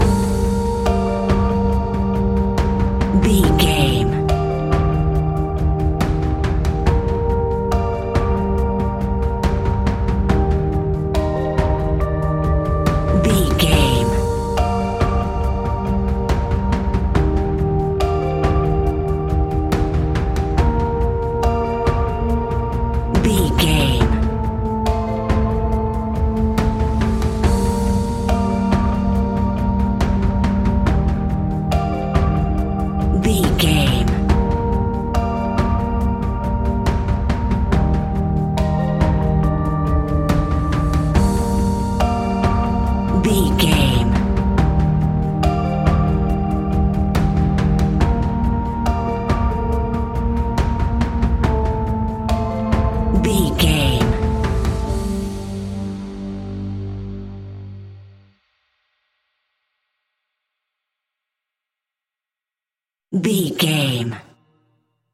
Aeolian/Minor
scary
ominous
dark
haunting
eerie
electric piano
synthesiser
drums
horror music
Horror Pads